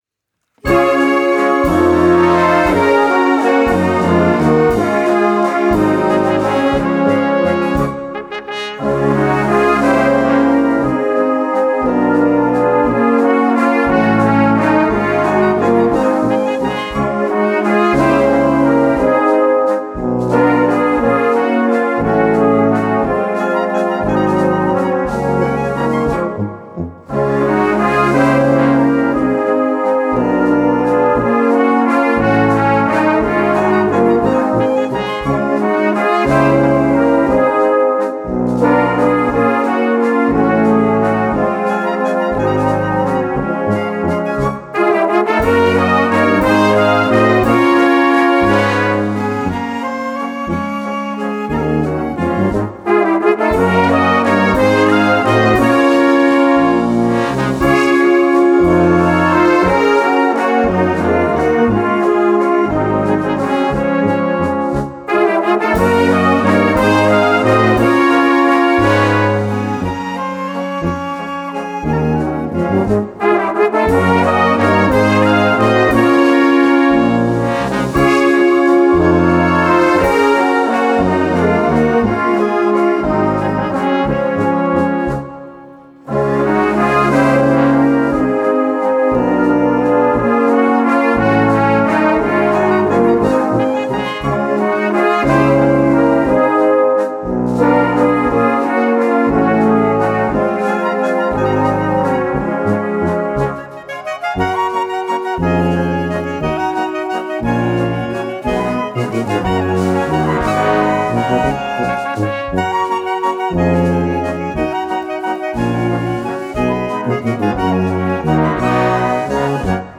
Walzer für Blasmusik